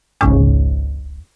critical_error.wav